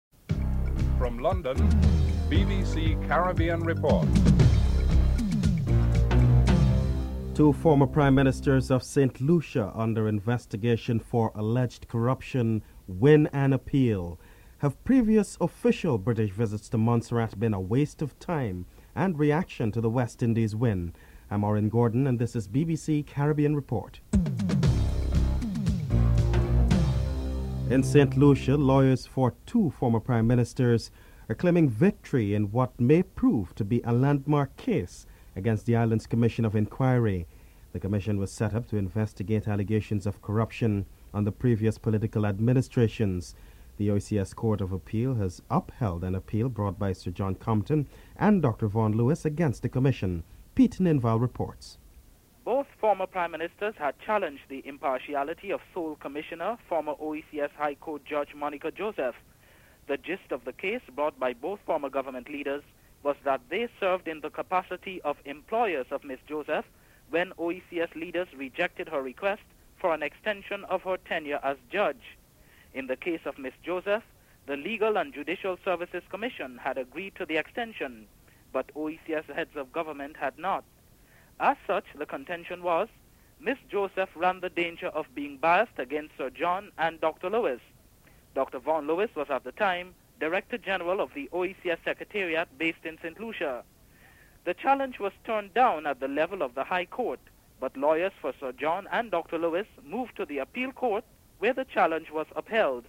4. Montserrat's Chief Minister David Brandt criticises Britain's slow response to the volcano crisis and comments on the purpose of the Foreign Secretary Robin Cooke's upcoming visit (05:48-09:38)